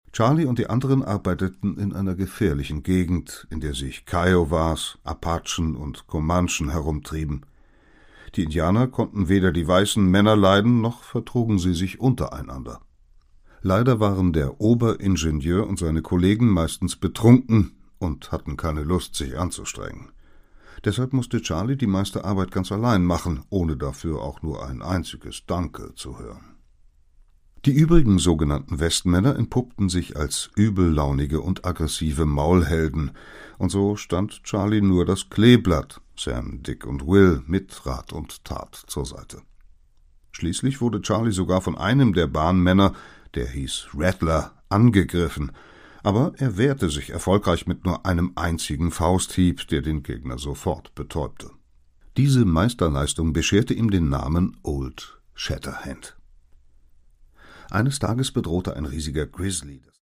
Produkttyp: Hörbuch-Download
Fassung: ungekürzte Fassung